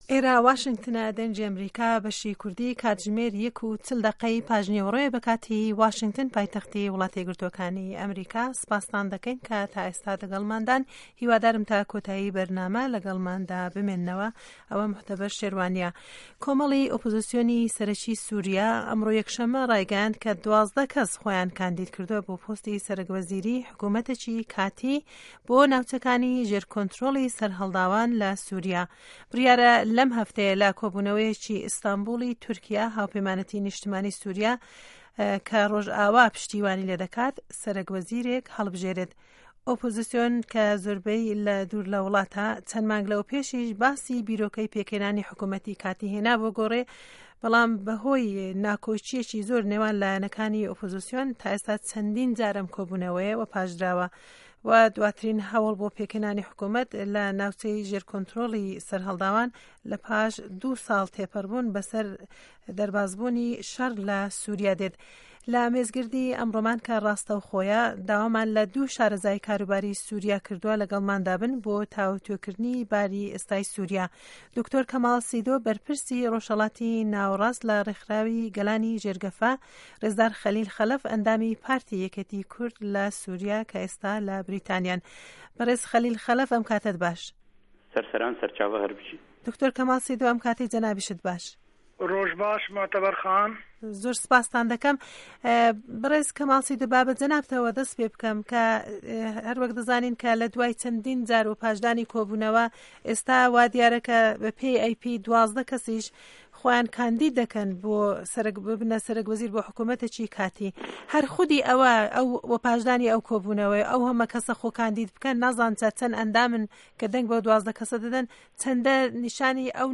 مێزگرد